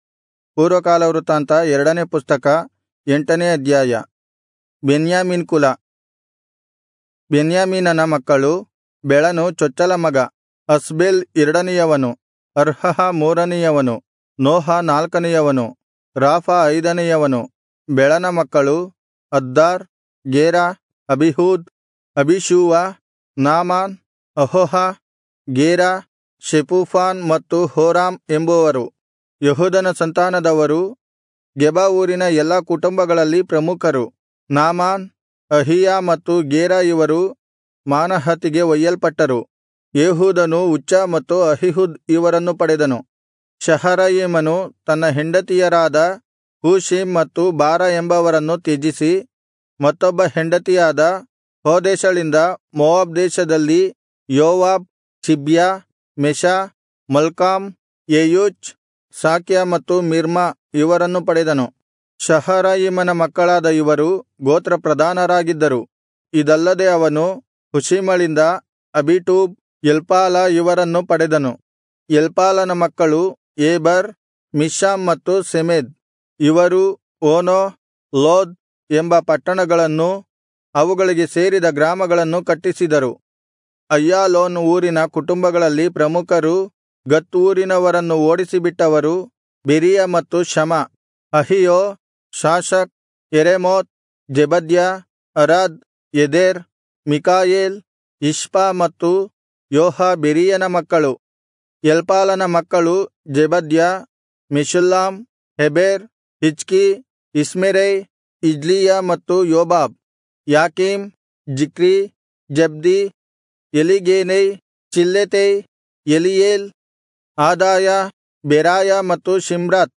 Kannada Audio Bible - 1-Chronicles 27 in Irvkn bible version